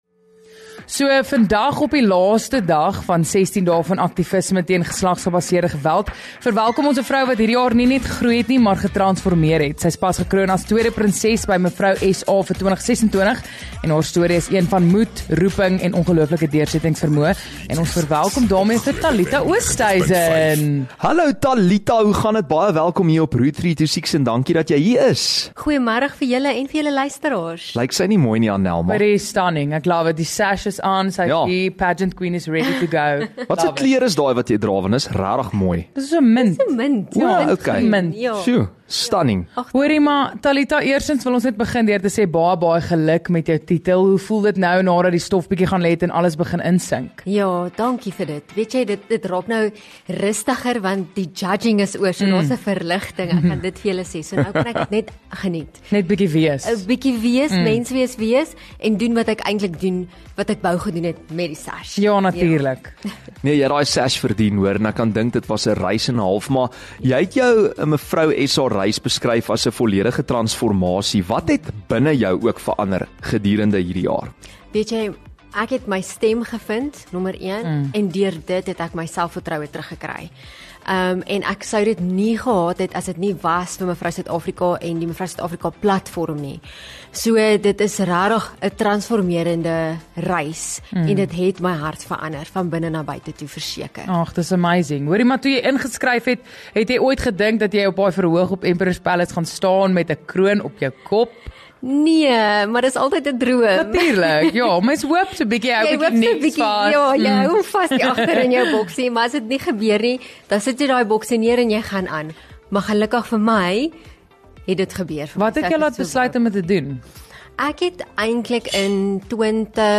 Hierdie dinamiese duo vergesel jou van drie tot ses en kleur jou laatmiddag in met konteks, kief klanke en koel kwinkslae. Hulle gesels met die OG's en die VIP's en bewys nie almal hoef te peak in matriek nie.